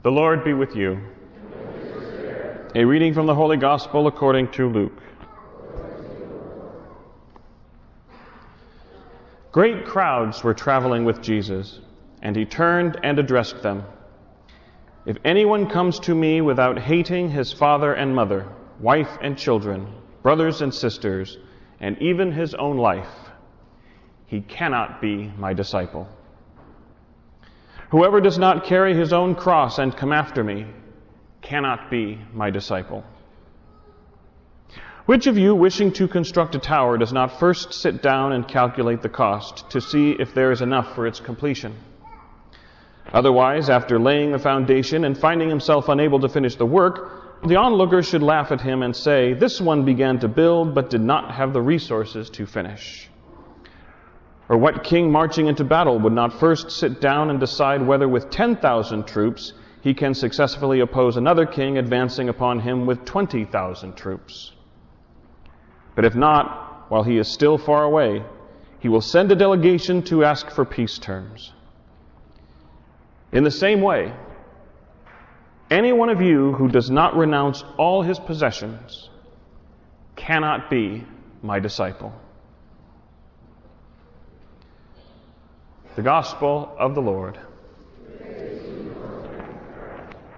GospelReading-23rdSundayC.wav